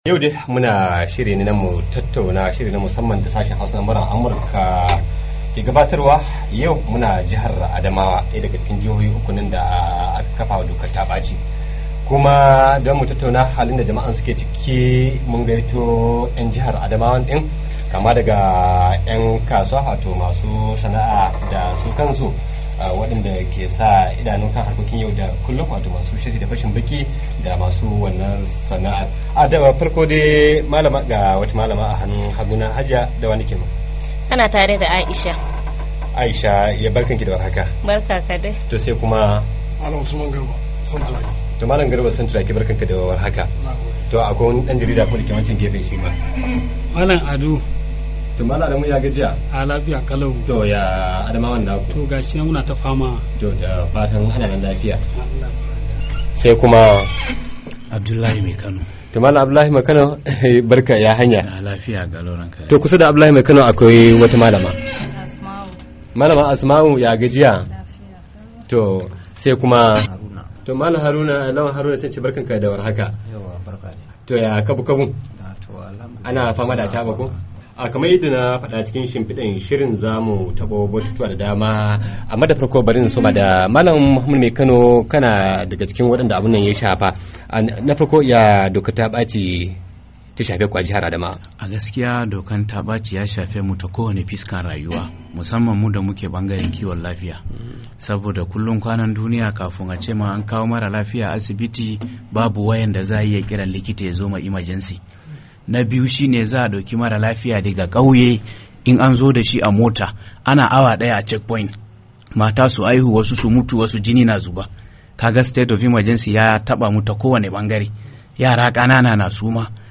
Rayuwa Karkashin Dokar Ta Baci: Muryar Amurka ta shirya tattaunawa kan yadda dokar ta baci da aka kakabawa jihohi uku na arewa maso gabashin Najeriya suka shafi rayuwar yau da kullum na al'ummominsu